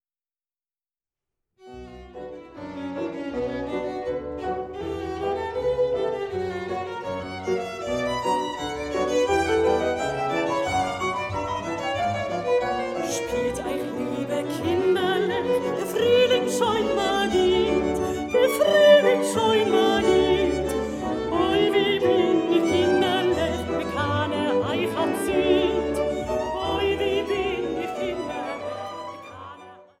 Sopran
Violine
Kontrabass
Klavier